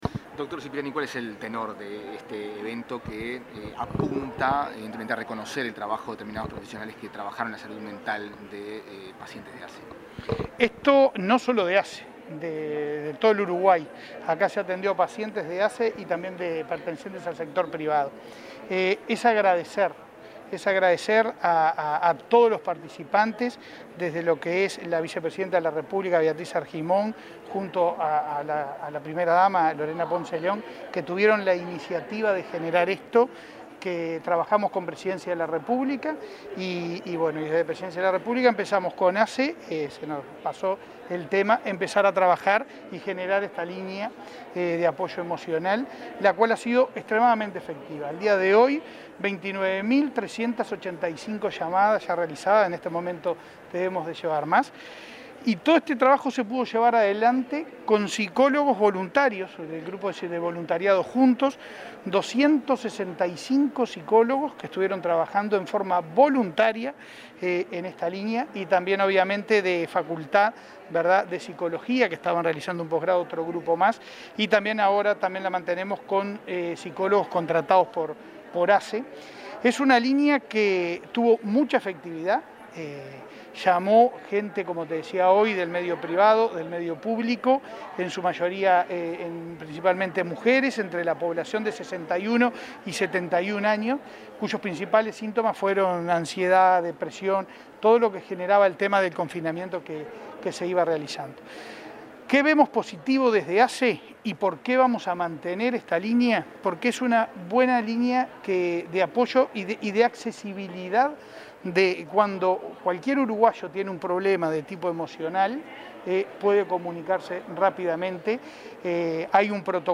Declaraciones del presidente de ASSE, Leonardo Cipriani